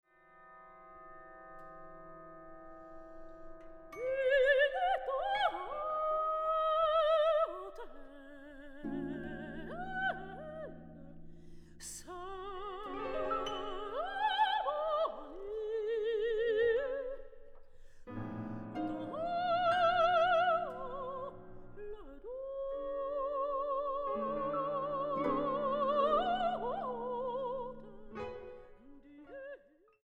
soprano
The central movement employs two contrasting vocal styles.